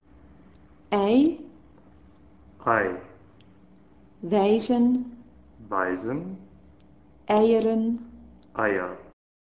Extra vraagje: Kun je het articulatieverschil tussen de Duitse diftong in het woordje " weisen " en in de Nederlandse diftong in " wijzen " beschrijven?